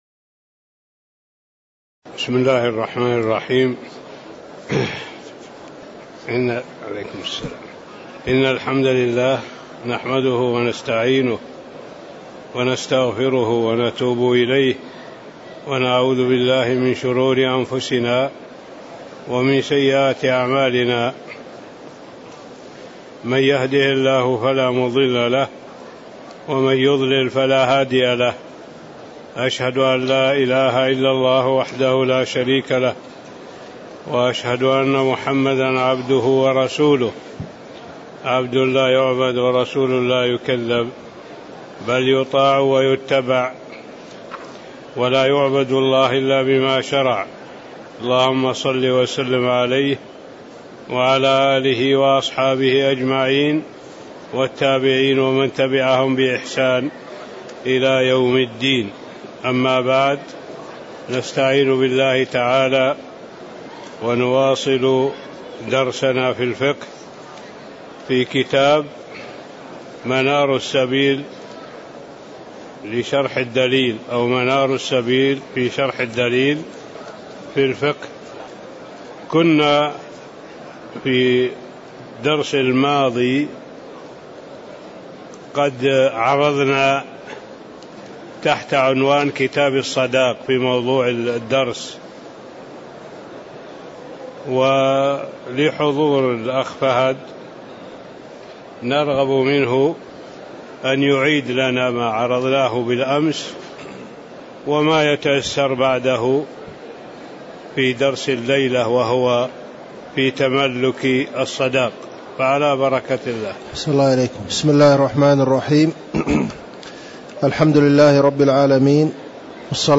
تاريخ النشر ٣ رجب ١٤٣٧ هـ المكان: المسجد النبوي الشيخ